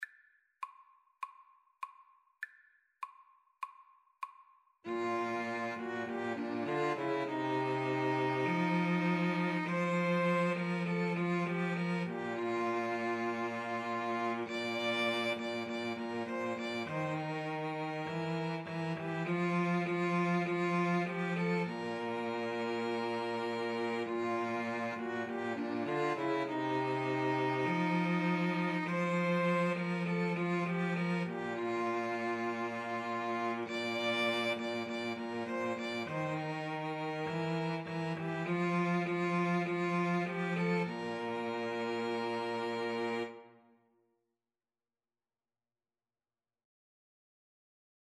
Bb major (Sounding Pitch) (View more Bb major Music for String trio )
4/4 (View more 4/4 Music)
String trio  (View more Easy String trio Music)
Traditional (View more Traditional String trio Music)